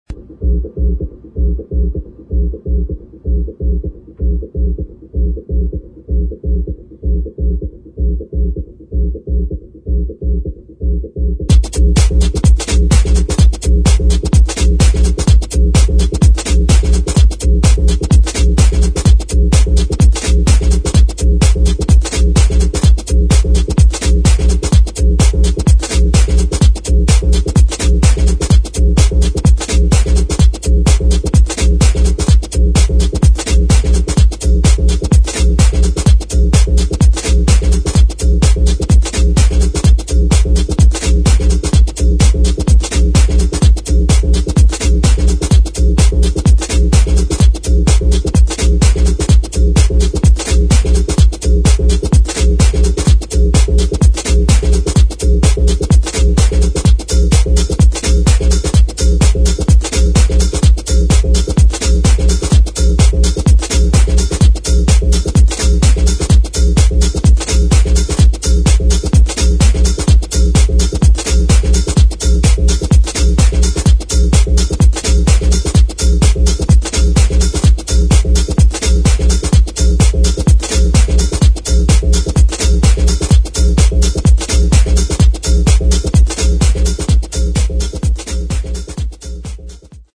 [ DEEP HOUSE / TECHNO ]